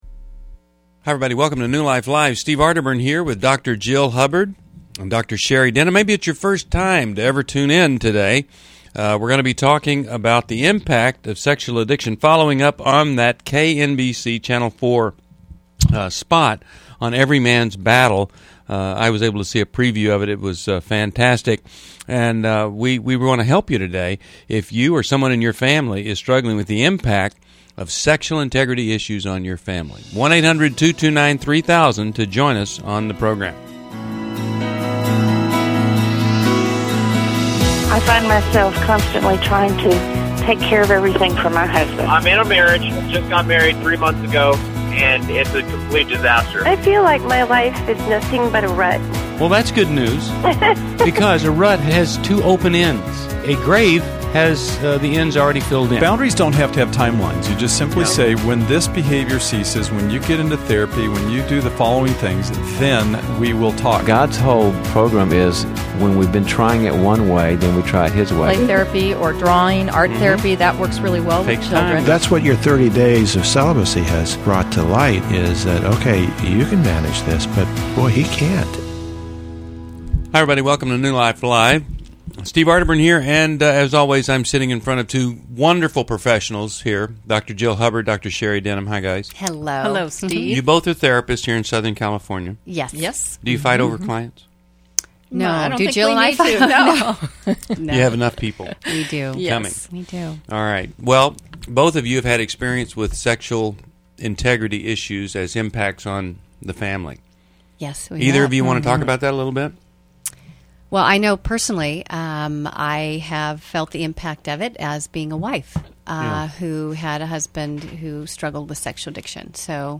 Caller Questions: How should I handle the custody battle for my son with my sex offender ex?